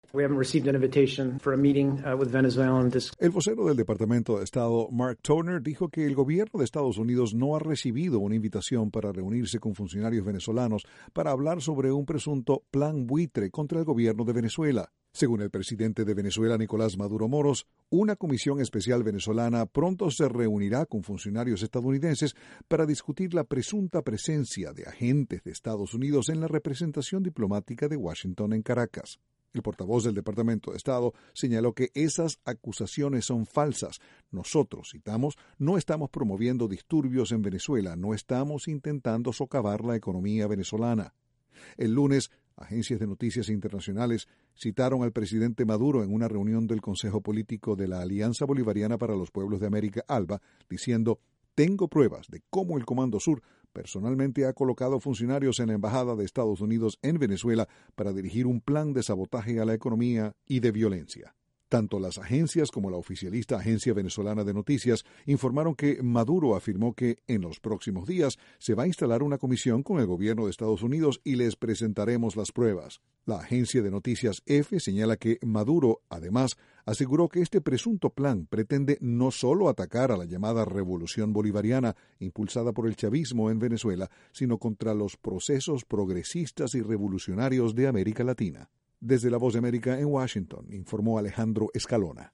De nuevo, el Departamento de Estado negó, categóricamente, que Estados Unidos esté promoviendo disturbios en Venezuela. Desde la Voz de América, Washington